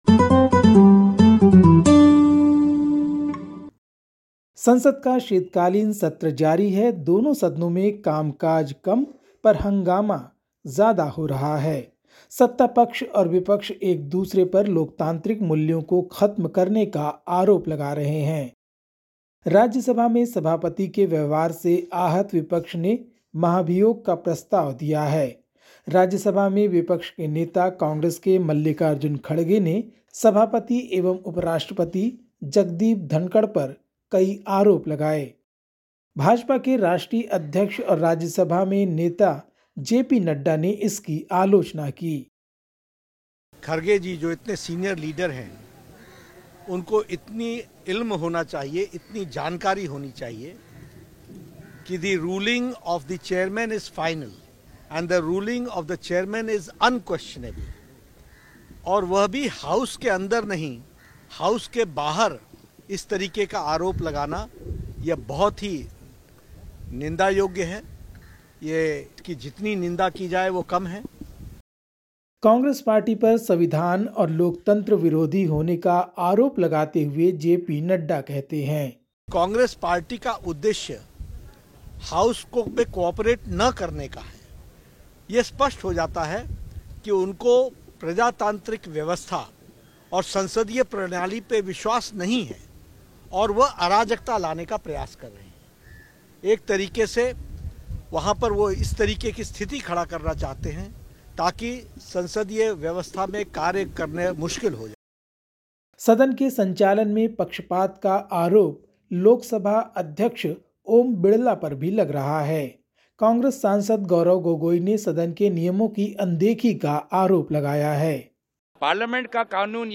Listen to the latest SBS Hindi news from India. 13/12/2024